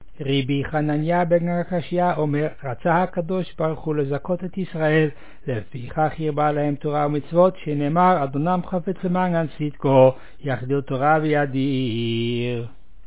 The service at the unveiling of a Matseva (tombstone) at Beth Haim in Ouderkerk aan de Amstel.
In contrast to the mitsva (levaya), there is no singing: all texts are recited.
Recited